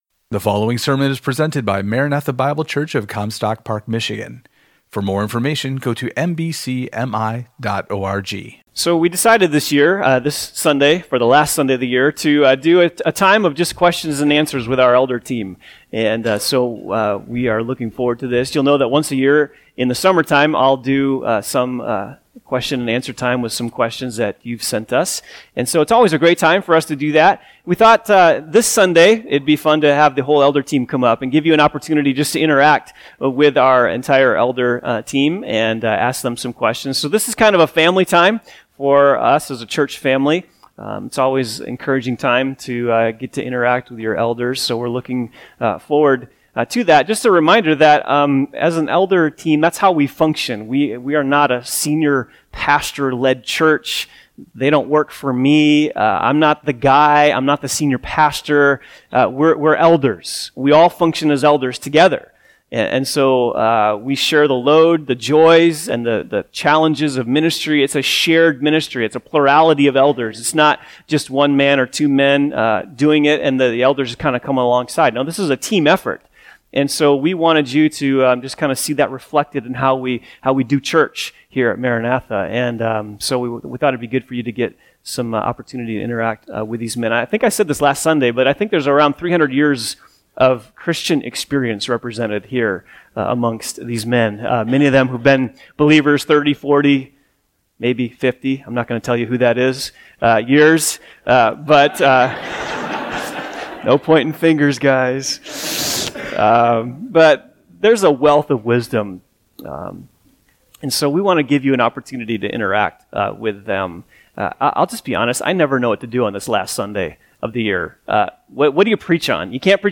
Question and Answer